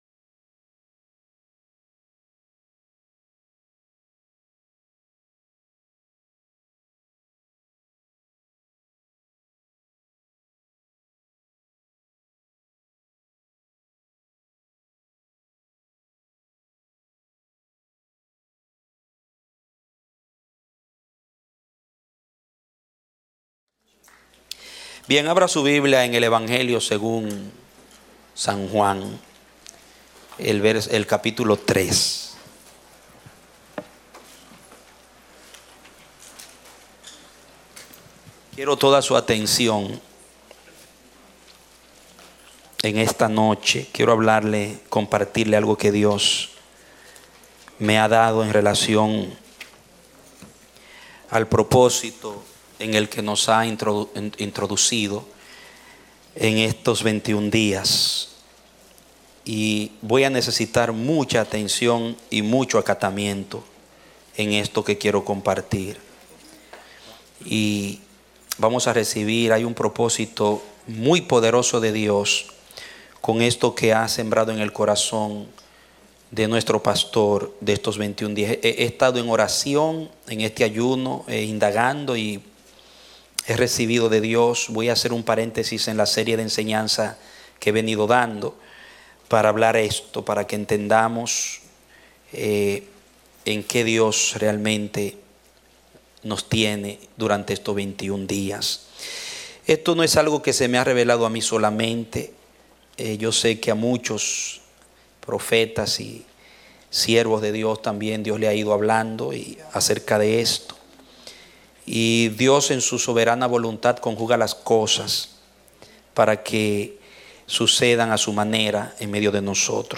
A mensaje from the serie "Mensajes."
Predicado Jueves 8 de Septiembre, 2016